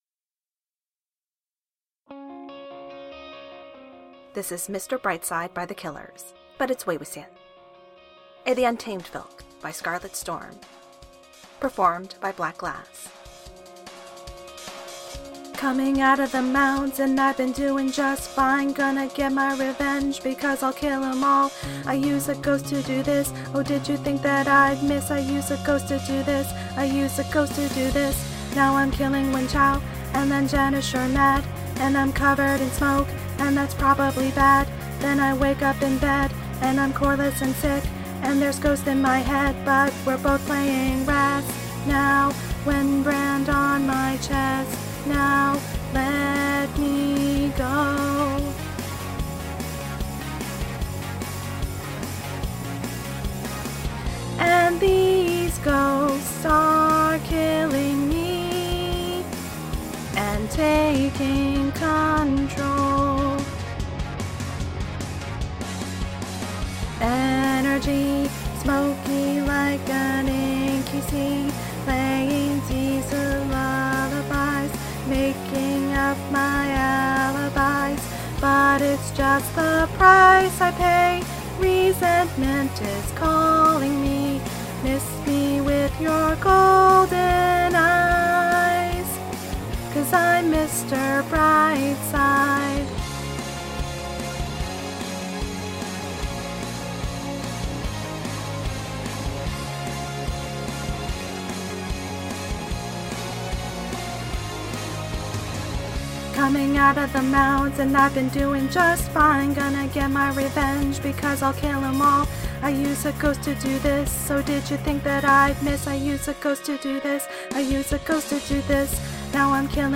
format|filk